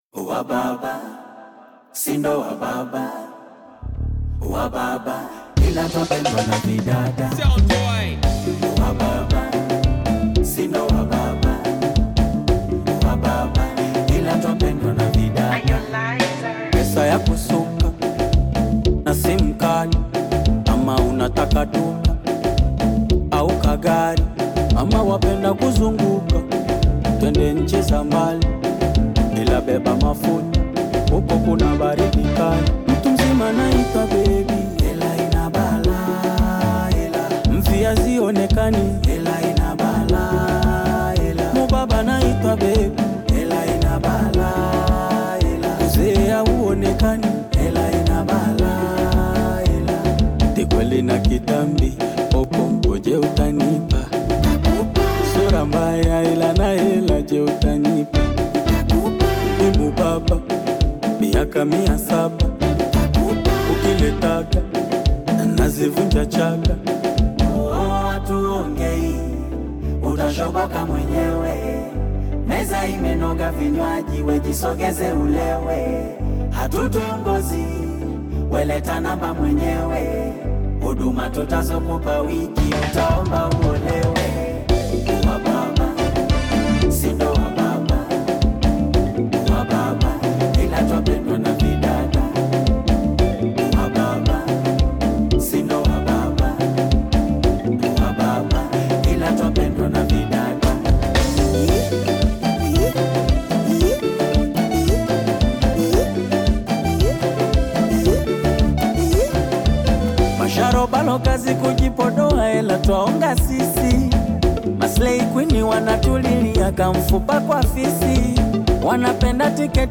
dynamic and lively experience
offering an immersive and dance-worthy musical escapade.